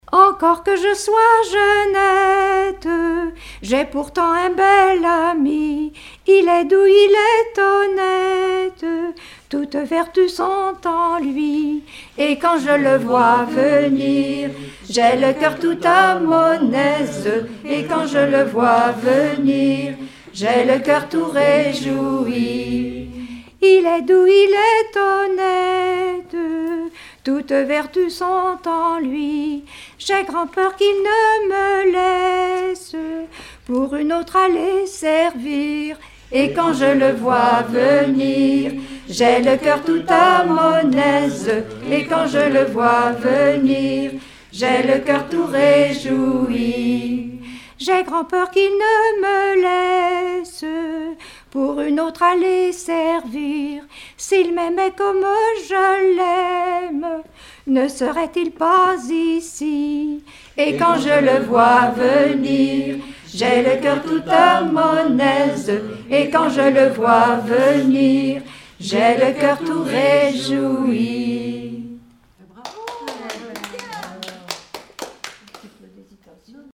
Genre laisse
Rassemblement de chanteurs
Pièce musicale inédite